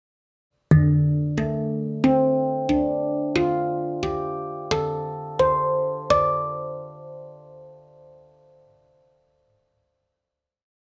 Moon II Handpan i D-moll (Ø 55 cm) byr på en varm og melankolsk klang som inviterer til intuitivt og meditativt spill.
Instrumentet er laget av rustfritt stål, som gir klar tone, lang sustain og balanserte overtoner.
• Stemning: D-moll – harmonisk, melankolsk og meditativ.
• Klar tone med lang sustain.
D3, A3, C4, D4, F4, G4, A4, C5, D5